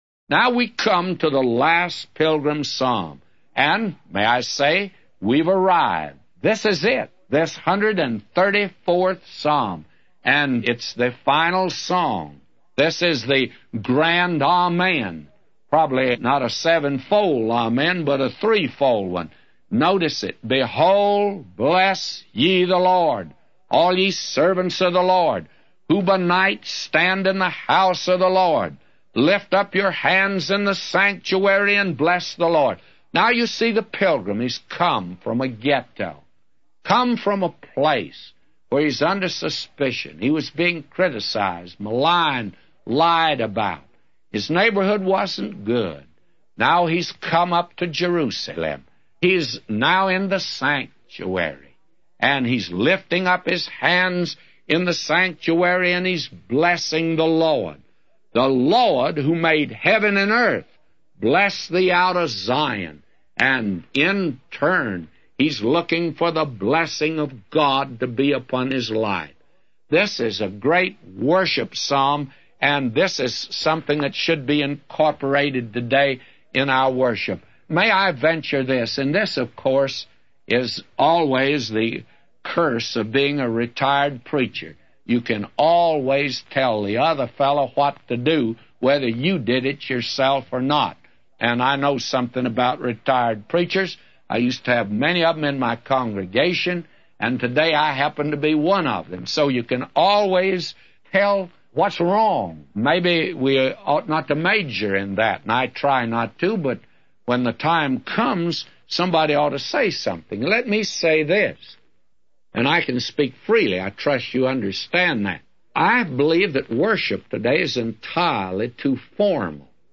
A Commentary By J Vernon MCgee For Psalms 134:1-999